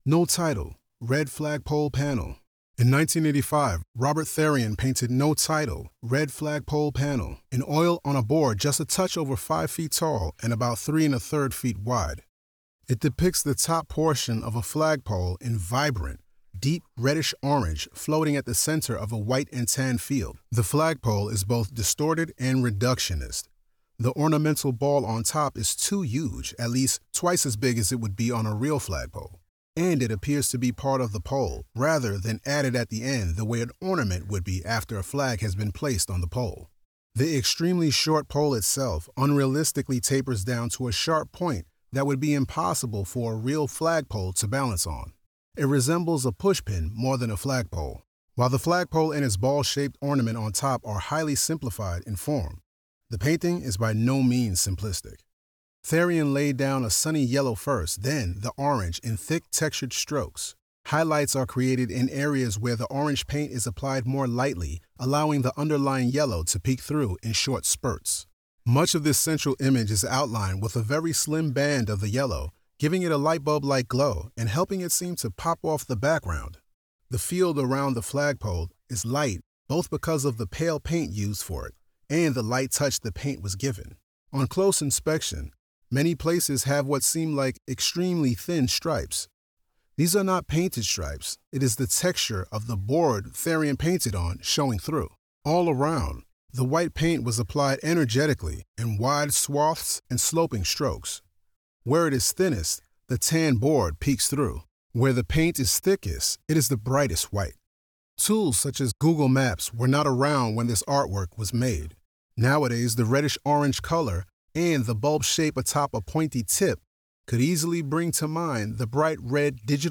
Audio Description (02:26)